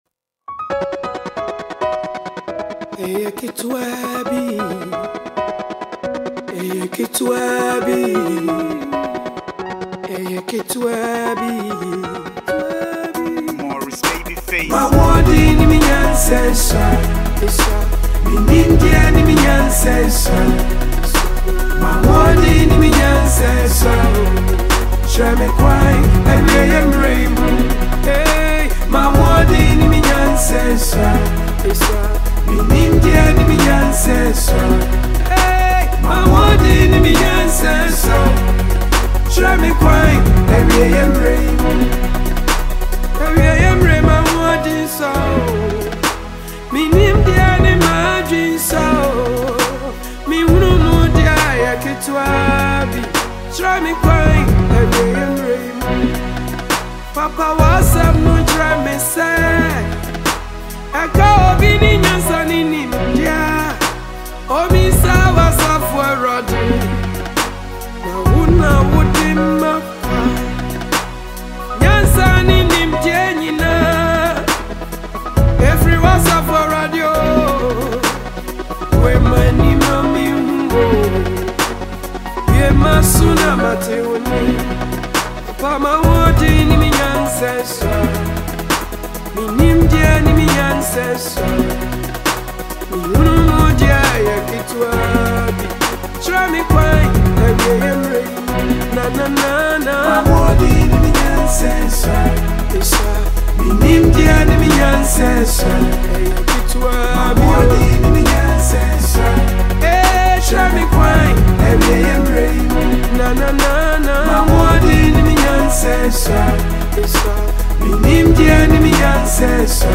GOSPEL SONG
– Genre: Gospel
emotive vocals